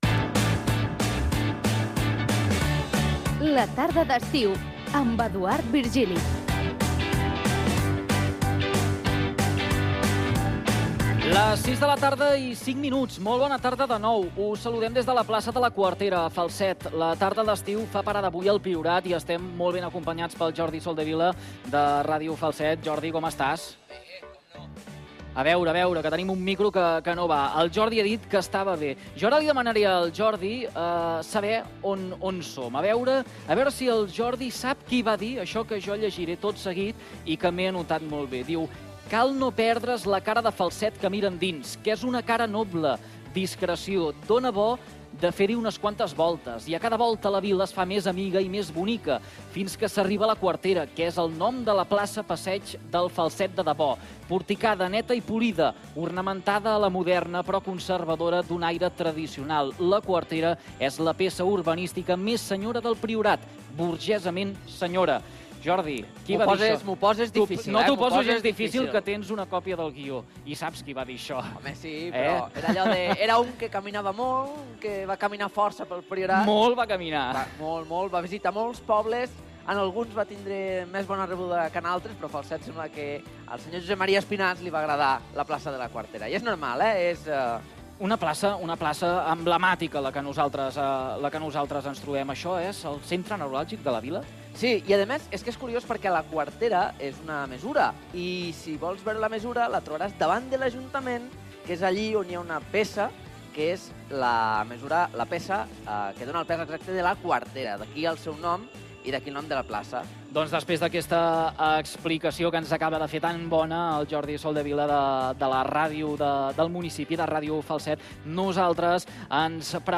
Programa realitzat des de la plaça de la Quartera de Falset.
Lectura d'un text d'Espinàs sobre la plaça de la Quartera de Falset. Sumari de la segona hora del programa.
Entreteniment